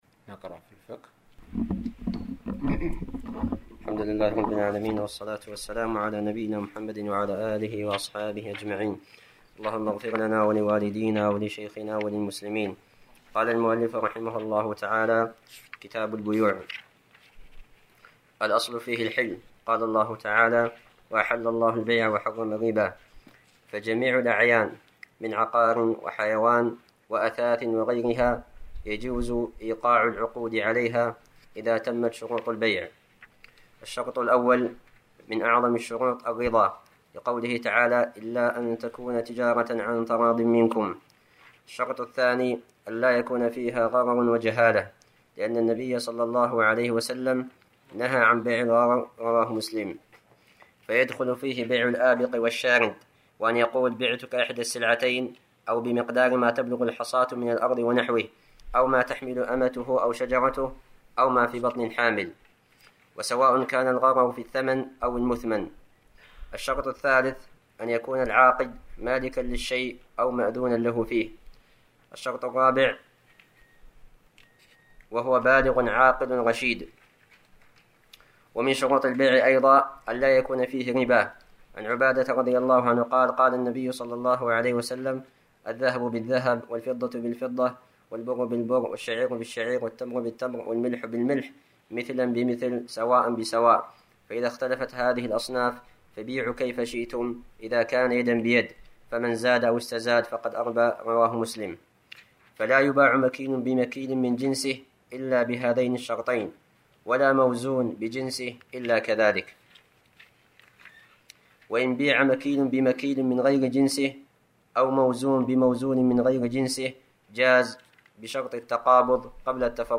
الدرس--37 [كتاب البيوع شروط البيع] 20/5/1433 التالى play pause stop mute unmute max volume